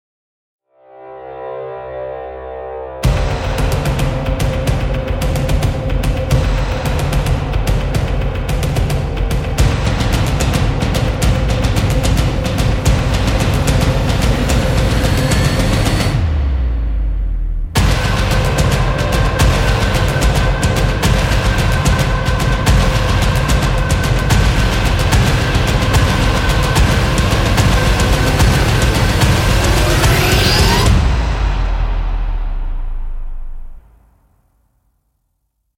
- 大鼓、小鼓、军鼓、鼓组、鼓圈、鼓边等各种鼓类乐器
- 钟琴、木琴、钢片琴、管钟等各种键盘类乐器
- 镲、锣、铃、铜钹等各种金属类乐器